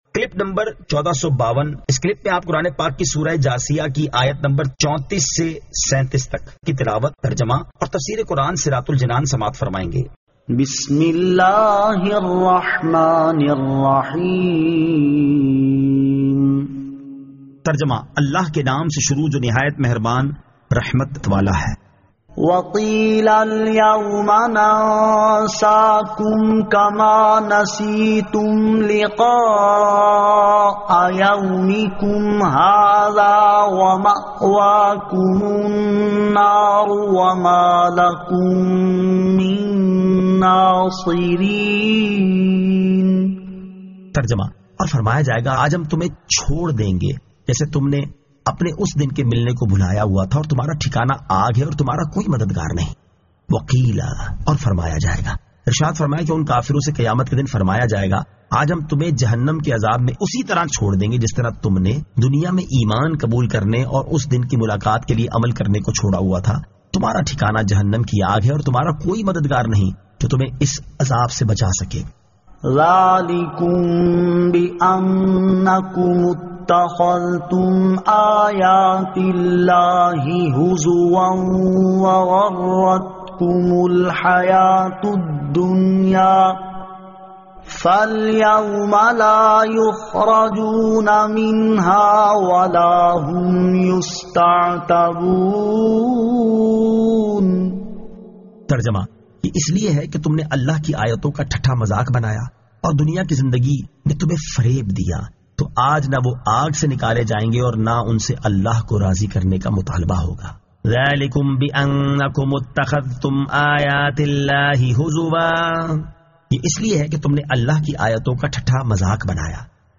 Surah Al-Jathiyah 34 To 37 Tilawat , Tarjama , Tafseer